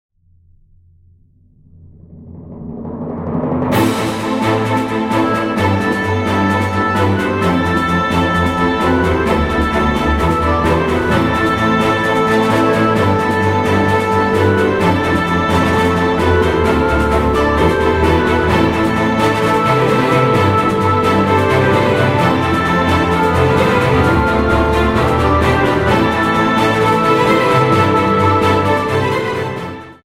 Mundart-Worshipsongs für Kids